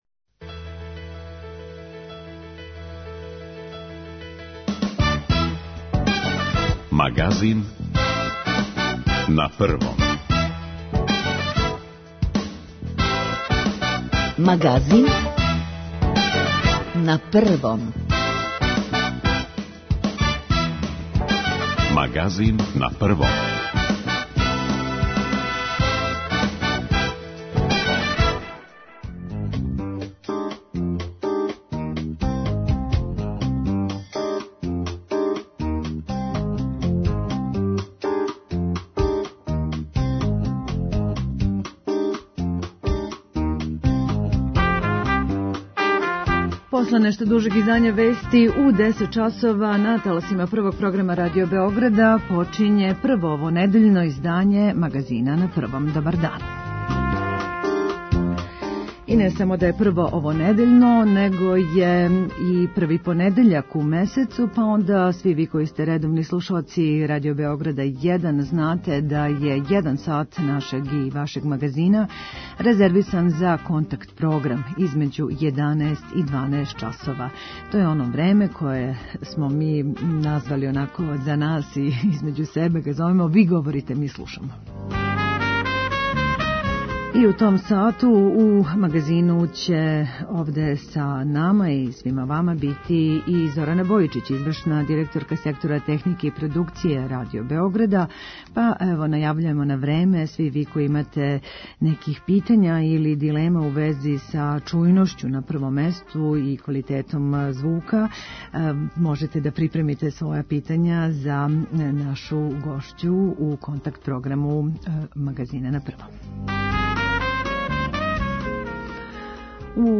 Ви говорите, ми слушамо - контакт-програм првог понедељка у месецу!
Централни сат 'Магазина на Првом' и овог првог понедељка у месецу резервисан је за укључења слушалаца Првог програма Радио Београда.